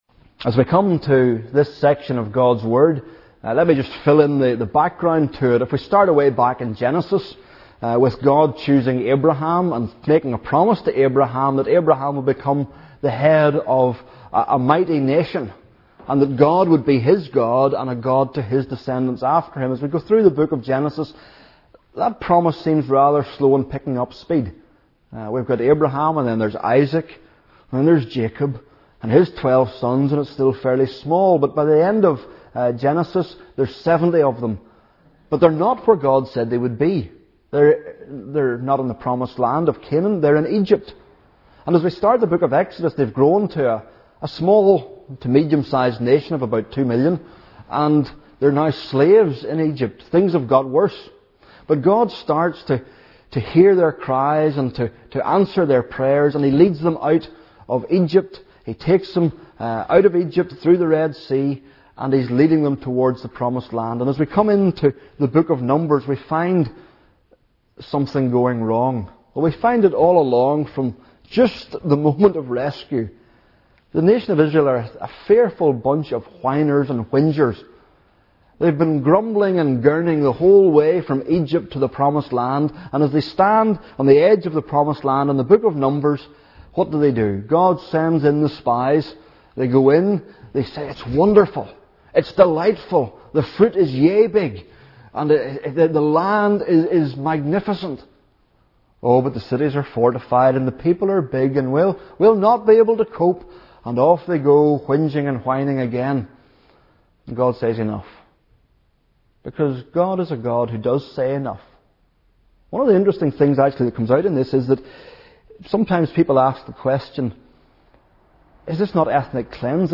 Hear the latest sermons preached in NLF, or browse the back catalogue to find something to feed your soul.